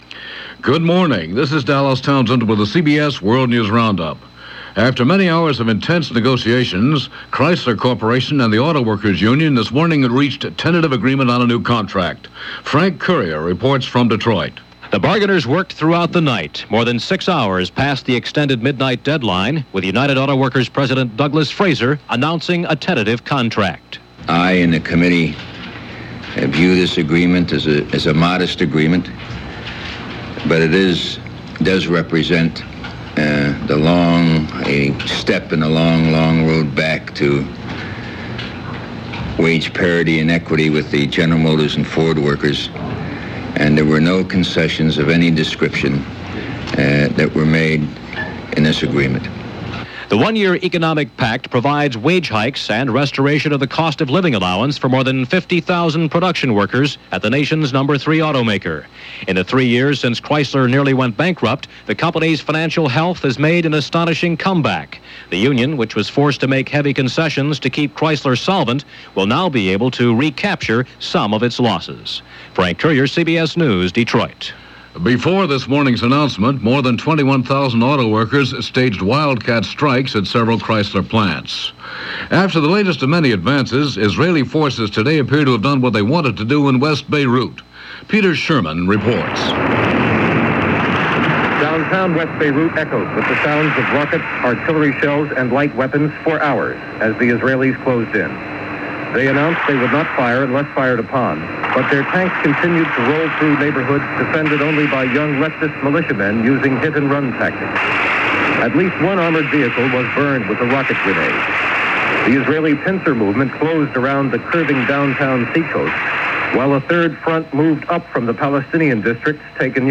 News for this day in 1982.